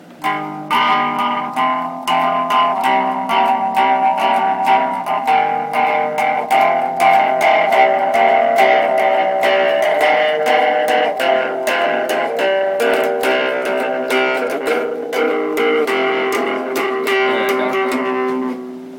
描述：最后一个最好的！另一个迷幻的吉他riff。这个是缓慢而简单的；很适合一些石头人/淤泥摇滚。
Tag: 100 bpm Rock Loops Guitar Electric Loops 3.19 MB wav Key : Unknown